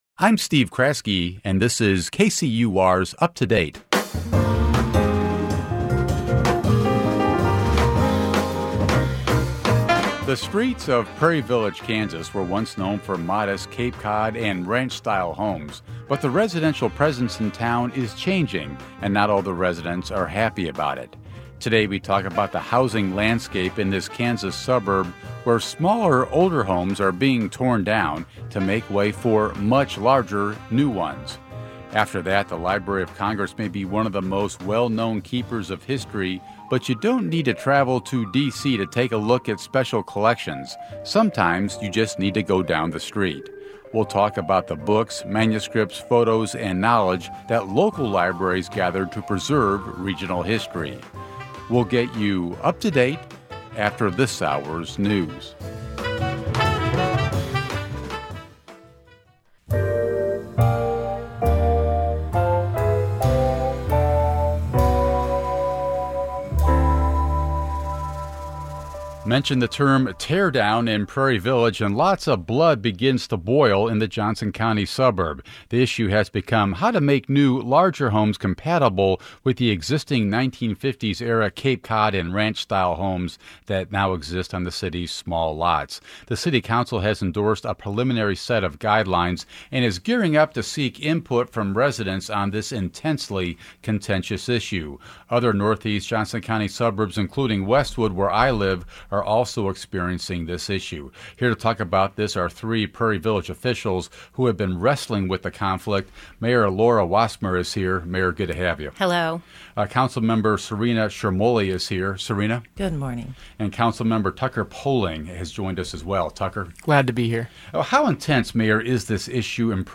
Today, we asked city leadership how they would strike a balance between property owners' ability to build what they want on their own land, and preserving the look and feel of what's long been known as a modest, affordable community.
Today's panel of librarians discussed preserving those repositories, and reviewed some of the resources found in Kansas City's various special collections.